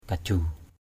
/ka-ʥu:/ (d.) quả cân = poids (pour peser). main kaju mi{N kj~% chơi cầu = jouer aux poids (espèce de jeu d’osselets).